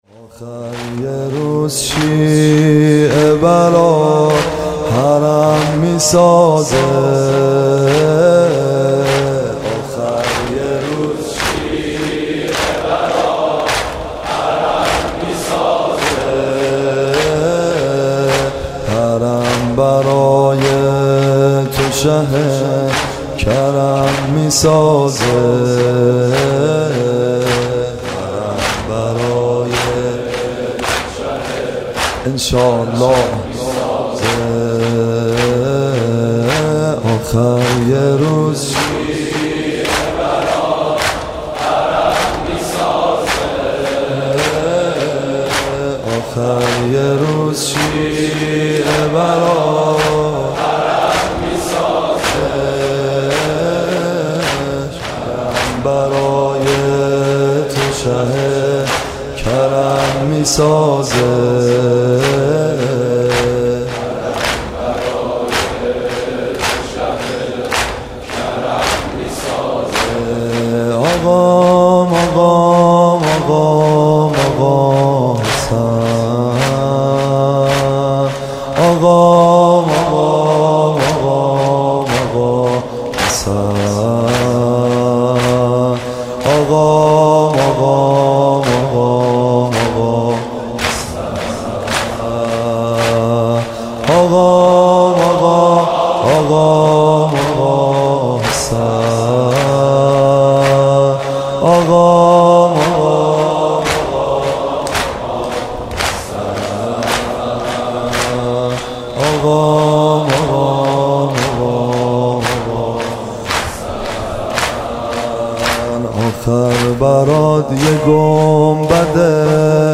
«ویژه مناسبت تخریب بقیع» زمینه : آخر یک روز شیعه برات حرم می سازه